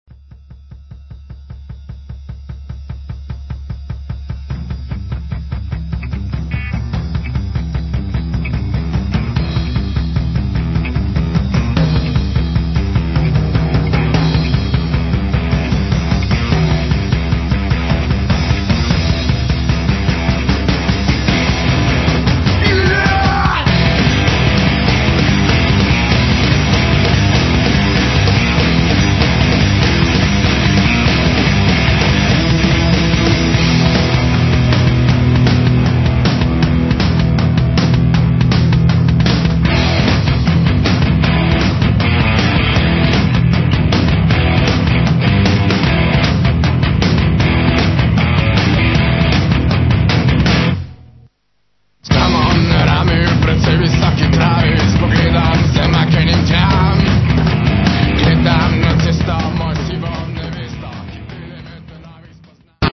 Enominutni Lo-Fi MP3 izsečki za hitro predstavitev ...